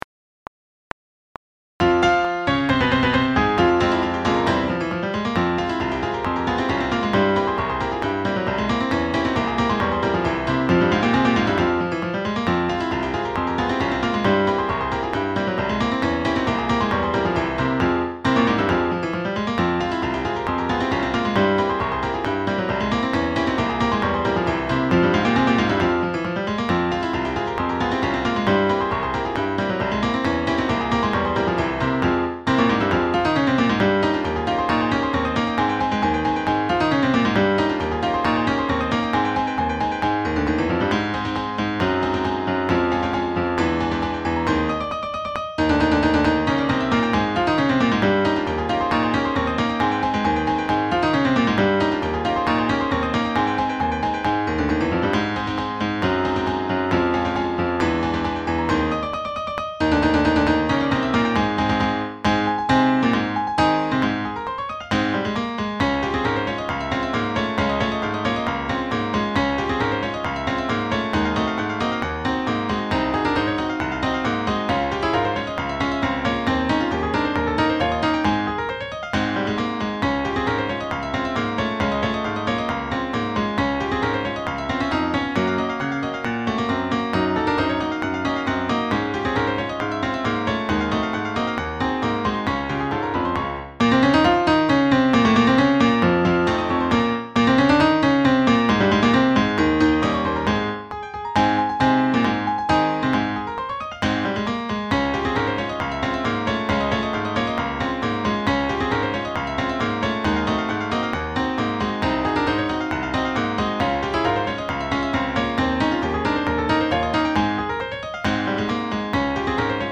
Sax Choir
Henry Fillmore was a US bandleader well known for composing both traditional and "screamer" marches in the early 1900's. This is one of the latter, a march composed as a show-piece to be played at extreme speed, rather than for marching.
This 2018 arrangement puts that part on tenor sax, although it's a little easier on a keyed instrument!
Backing track
68-4-rollingthunder-backingtrack.mp3